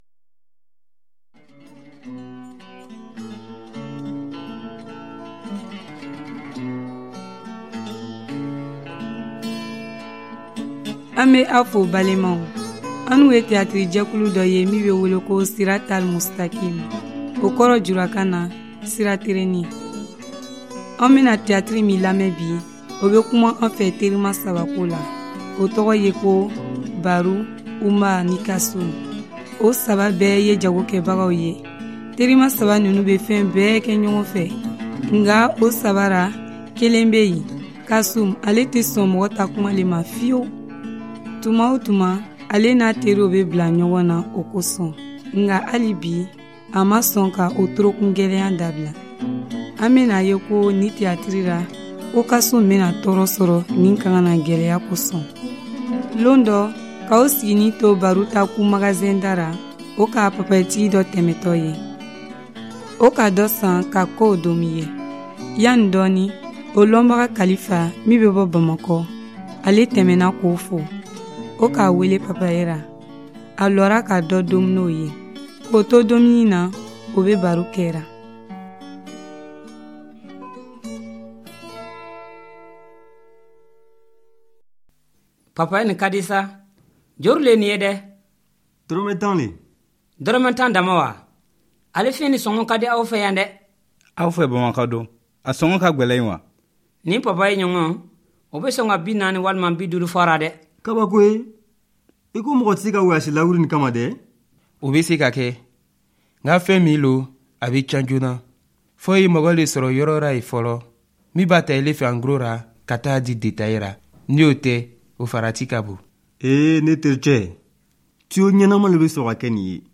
Tehatiriw lamɛn ka Ala ta mɔgɔw ta ɲanamanya faamu